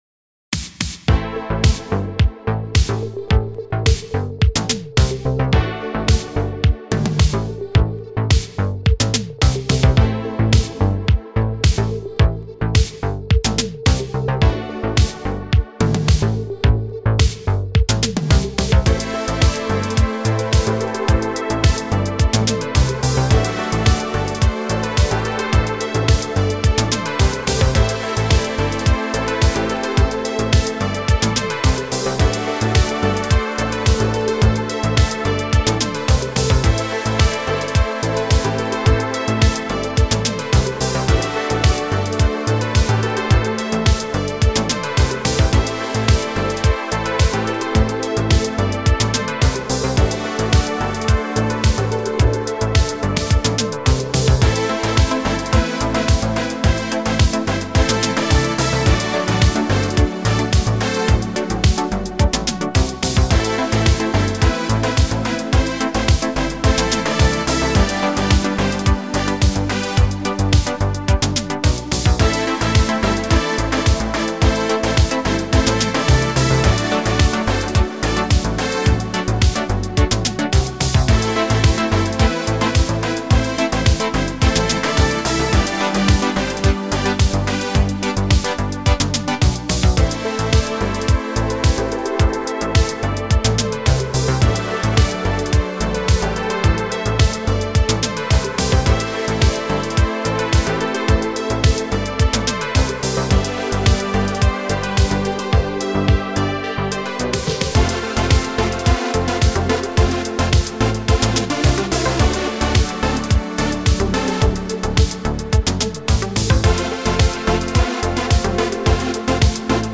موسیقی بی کلام الکترونیک